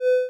Menu_Hover.wav